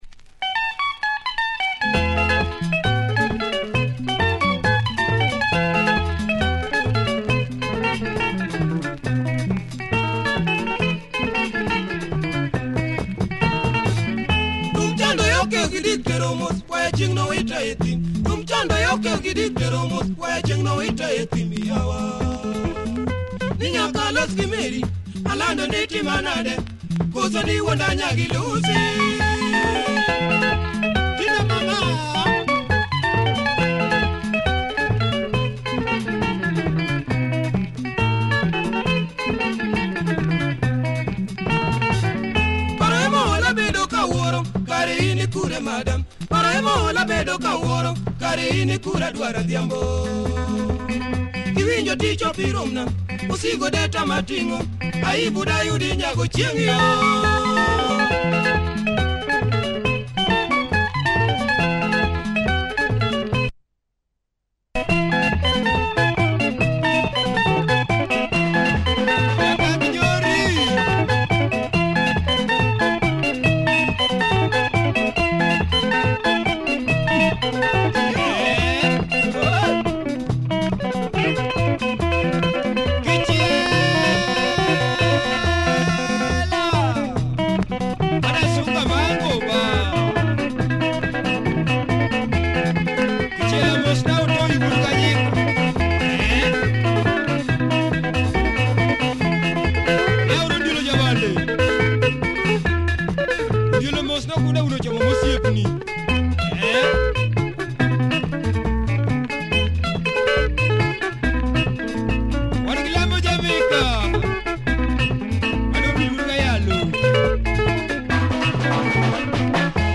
Proper LUO benga, steps up the tempo mid-way.